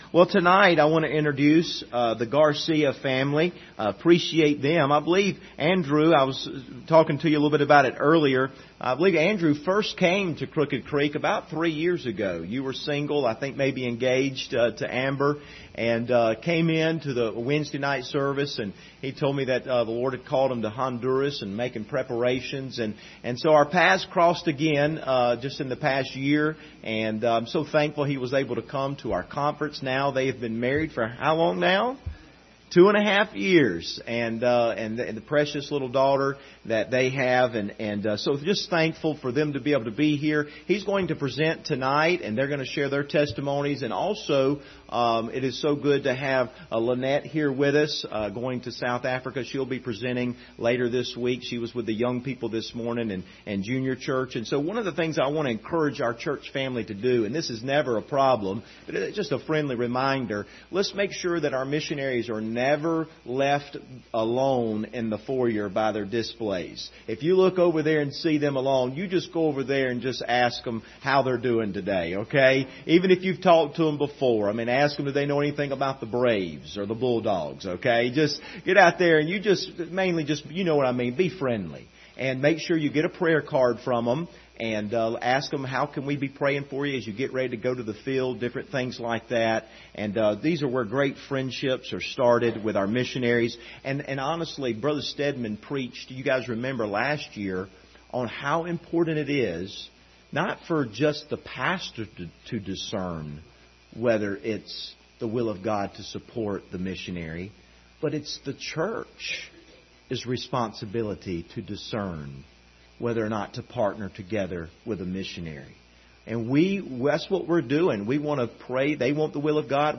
Series: 2022 Missions Conference Service Type: Sunday Evening